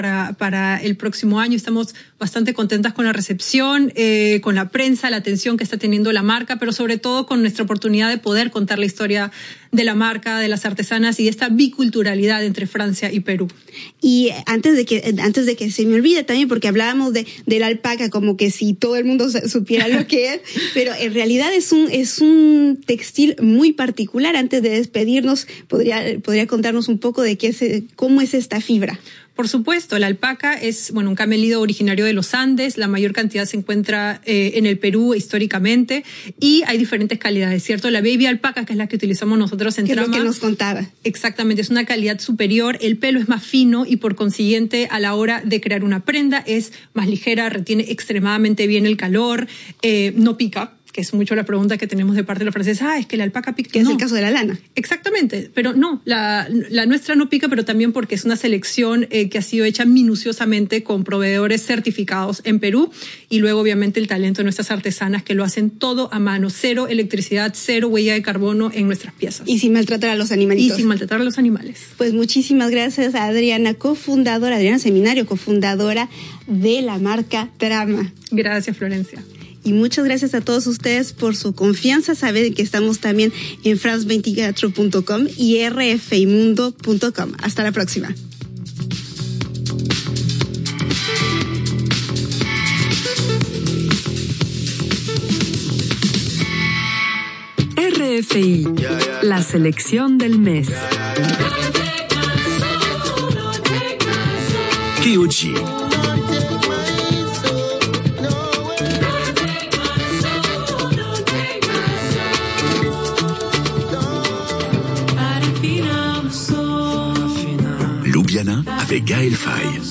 Ronda informativa
Informatiu